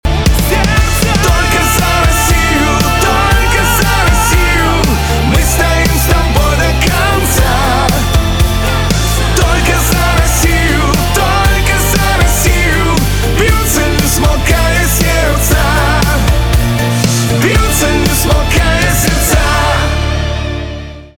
поп
гитара , барабаны , чувственные , сильные